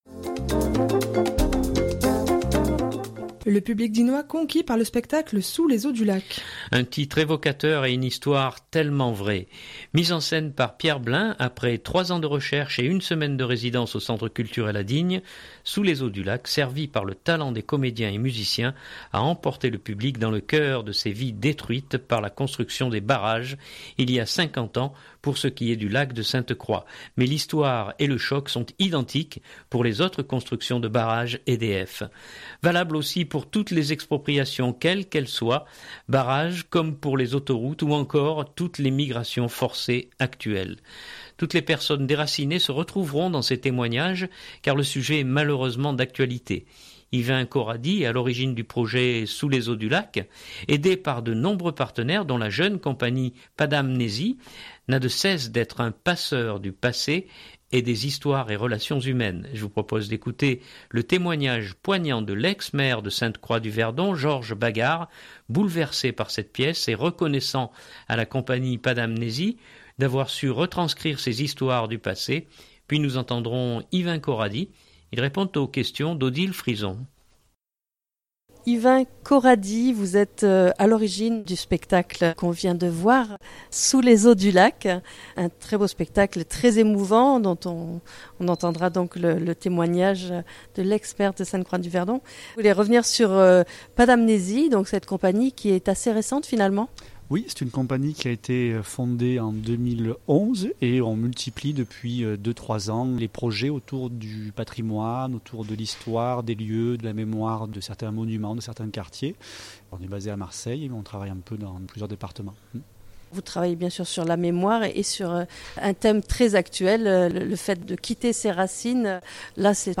Je vous propose d’écouter le témoignage poignant de l’ex-maire de Sainte-Croix du Verdon, Georges Bagarre, bouleversé par cette pièce et reconnaissant à la compagnie Padam Nezi d’avoir su retranscrire ces histoires du passé.